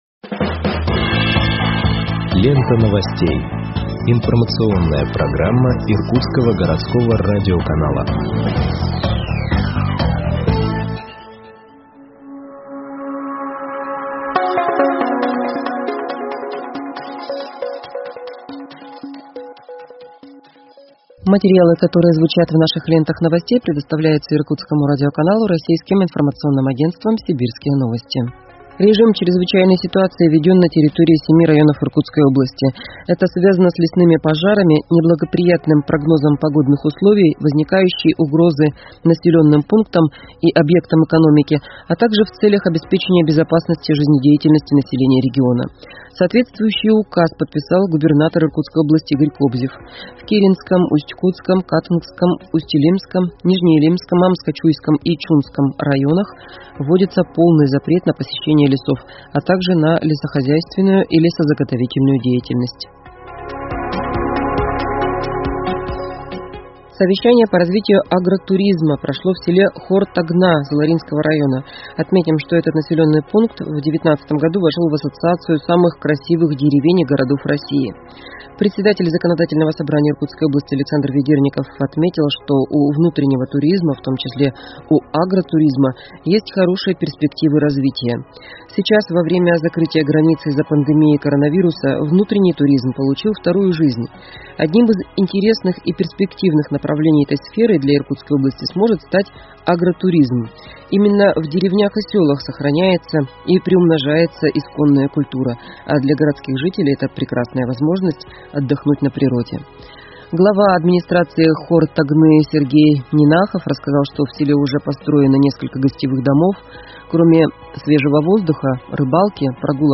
Выпуск новостей в подкастах газеты Иркутск от 10.08.2021 № 1